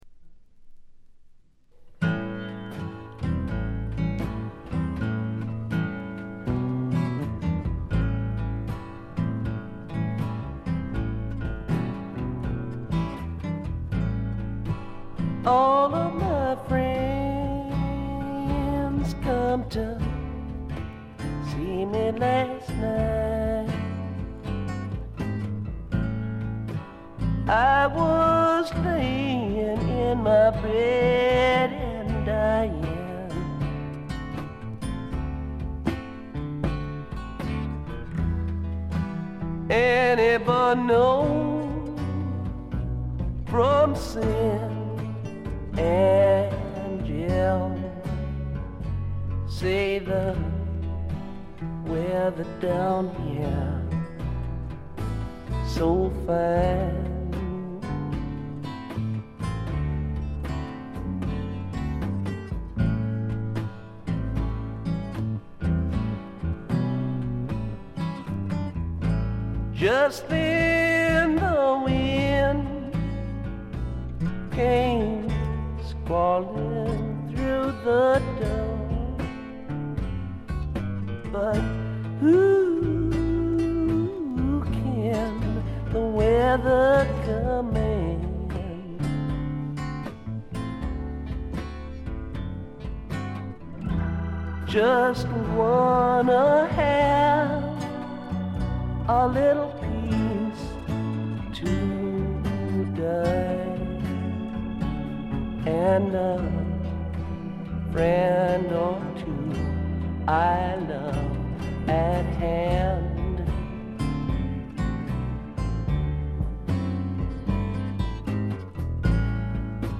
軽微なチリプチ程度。
試聴曲は現品からの取り込み音源です。